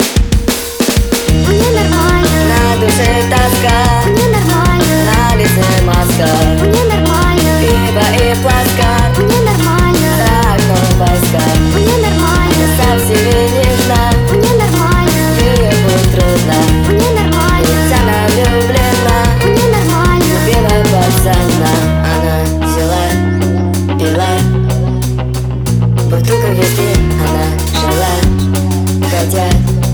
Жанр: Иностранный рок / Рок / Инди / Русские
# Indie Rock